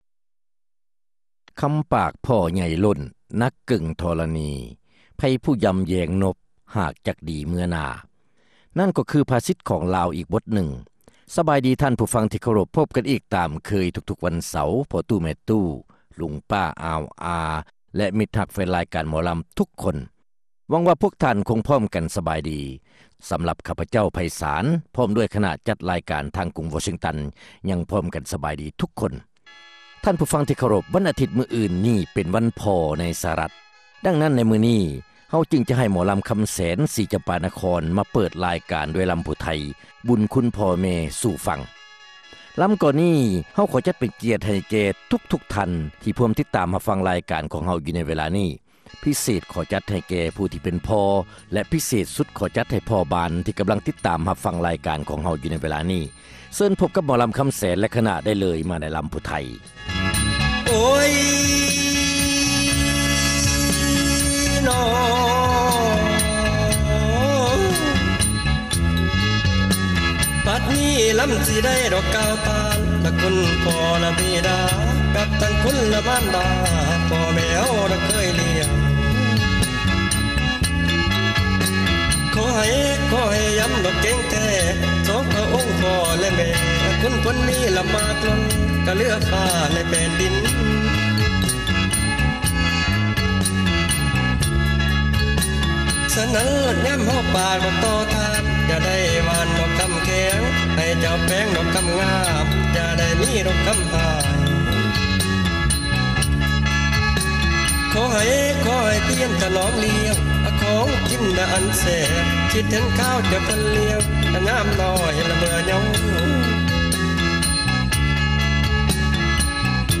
ລຳລາວປະຈຳສັປະດາ ຈັດມາສເນີທ່ານ ໂດຍ